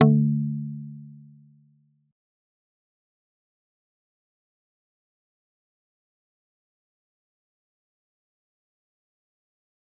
G_Kalimba-D3-pp.wav